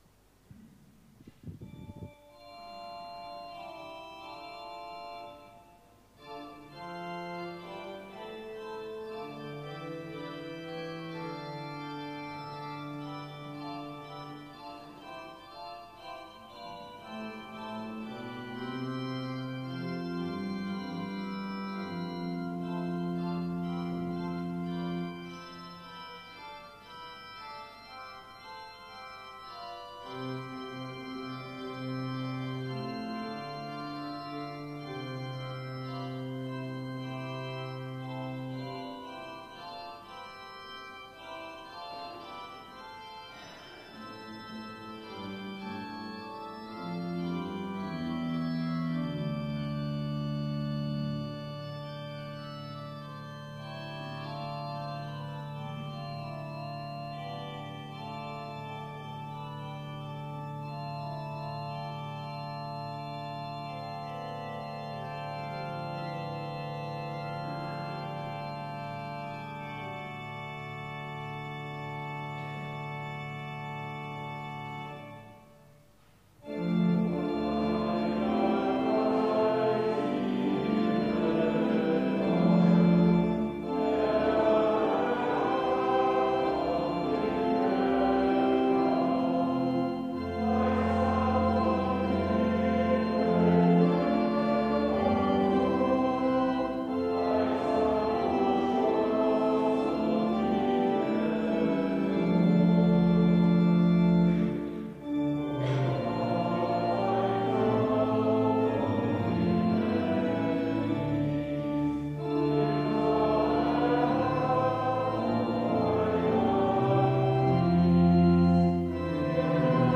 Audiomitschnitt unseres Gottesdienstes vom 1.Avent 2024